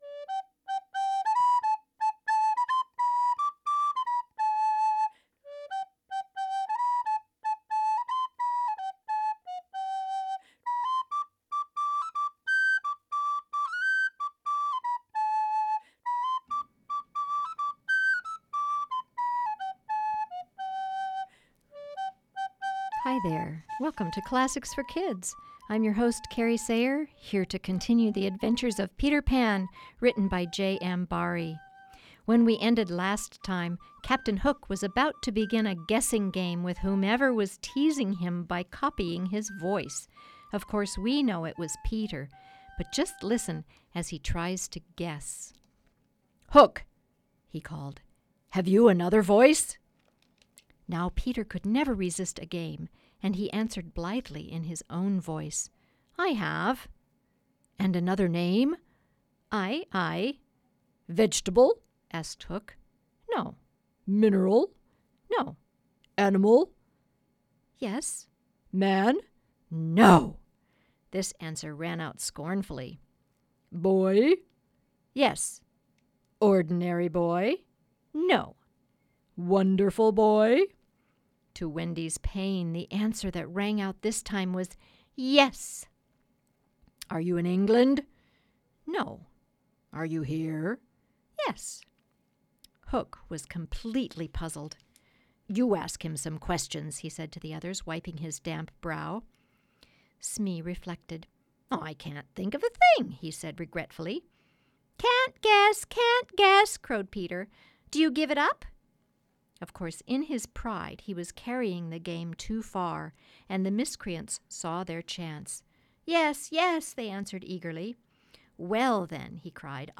continues reading the story of Peter Pan, by J.M. Barrie.